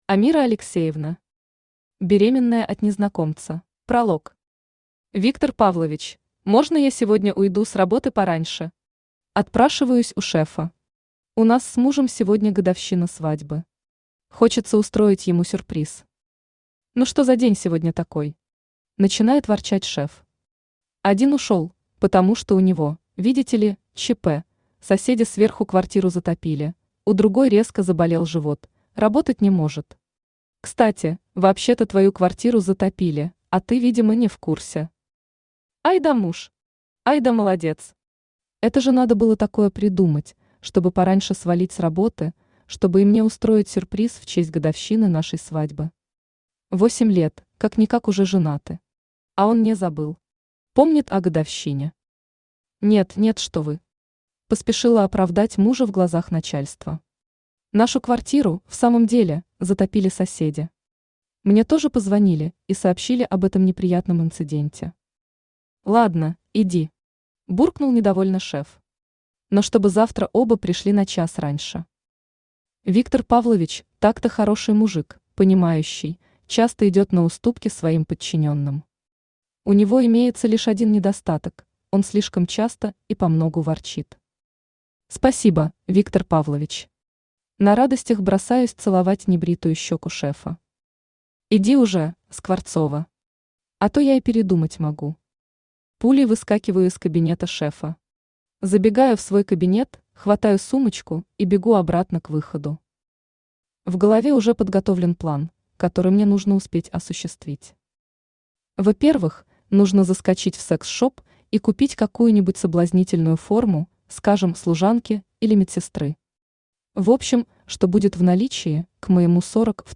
Аудиокнига Беременная от незнакомца | Библиотека аудиокниг